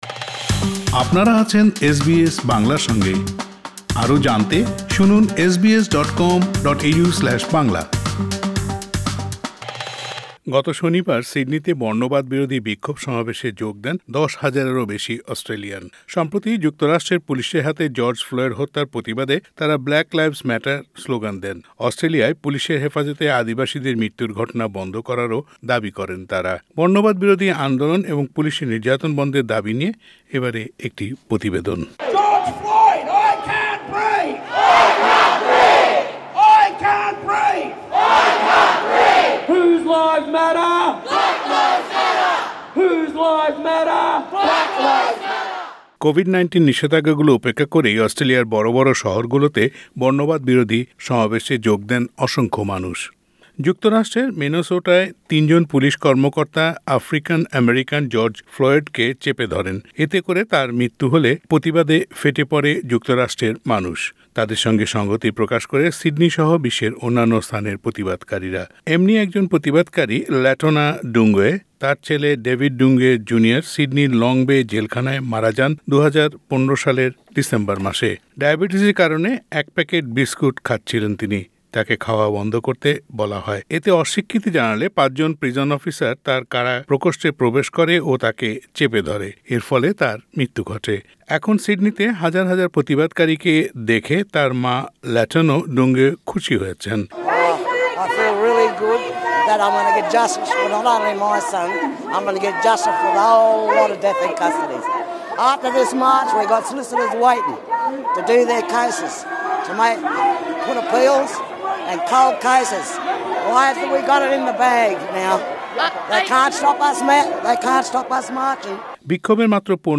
অস্ট্রেলিয়ায় পুলিশি হেফাজতে আদিবাসীদের মৃত্যুর ঘটনা বন্ধ করারও দাবি করেন তারা। প্রতিবেদনটি শুনতে উপরের অডিও প্লেয়ারের লিংকটিতে ক্লিক করুন।